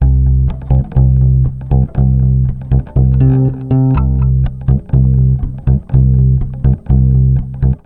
Бас, на сенде делей битвиговский стандартный + эхобой. Эхобой просто красит лентой. 1.